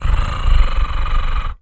cat_purr_low_01.wav